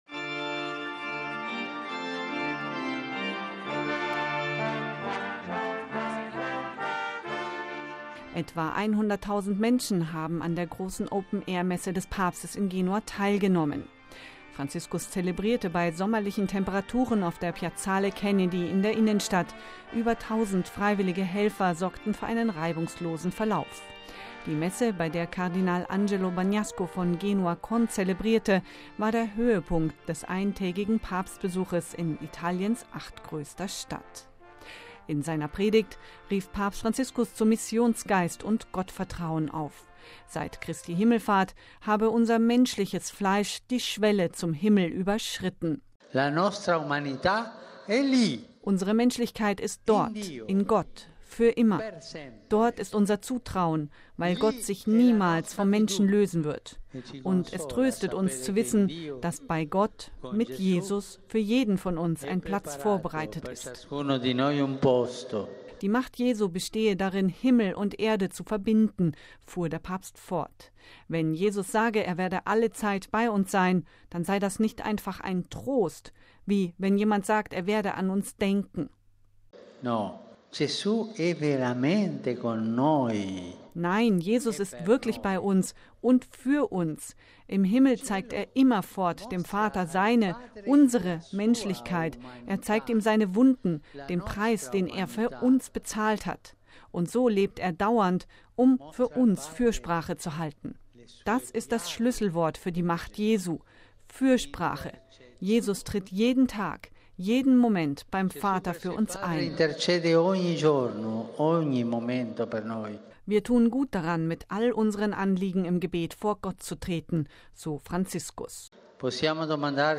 Papst predigt in Genua über „sanfte Macht des Gebets“
Etwa 100.000 Menschen haben am Samstagabend an einer großen Open-air-Messe des Papstes in Genua teilgenommen.